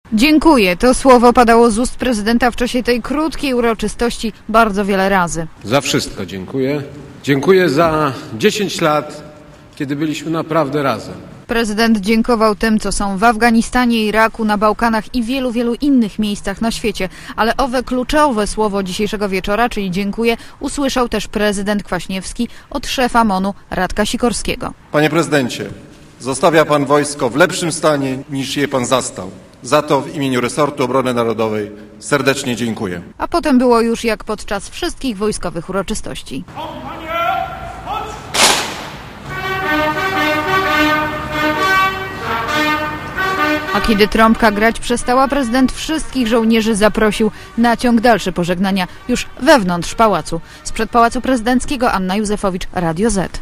Wojsko jest dumą Polski i jest ponad wszelkimi podziałami - powiedział podczas uroczystości pożegnania z żołnierzami Wojska Polskiego ustępujący prezydent i zwierzchnik sił zbrojnych Aleksander Kwaśniewski.
Relacja reportera Radia ZET